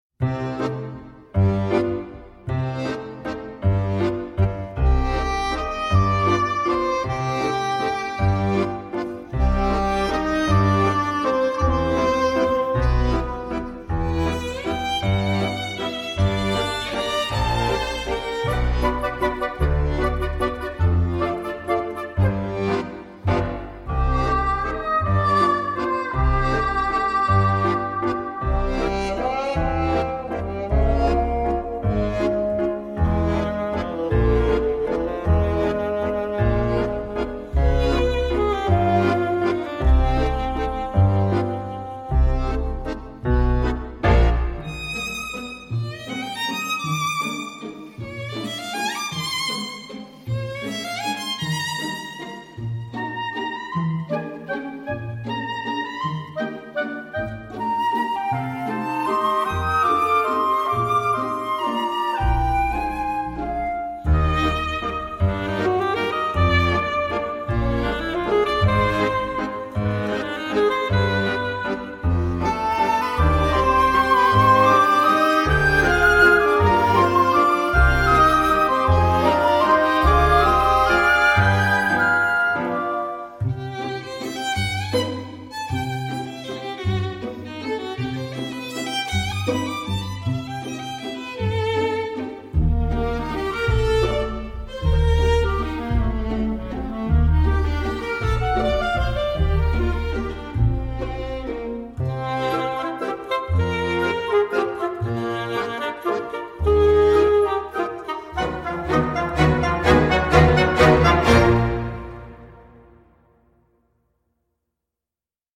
Touchant et sympathique.